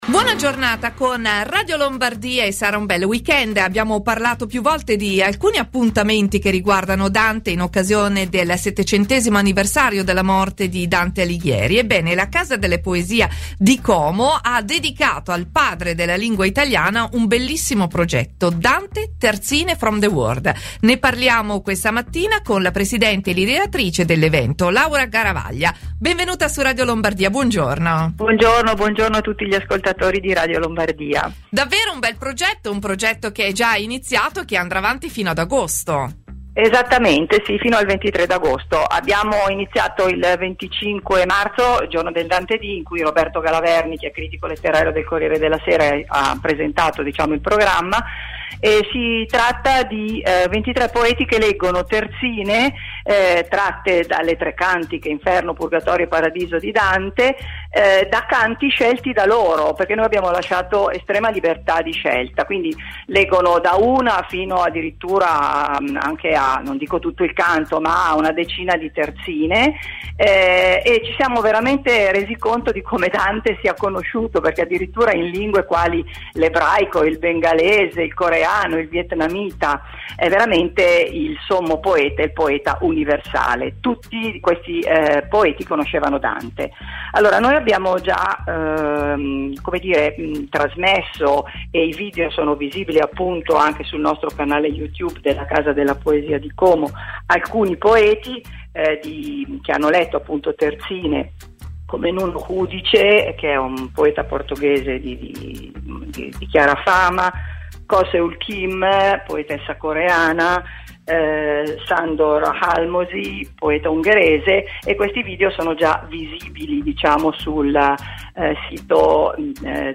Radio Lombardia Intervista